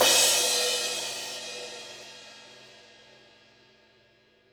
006_mdk_crashtwo08.wav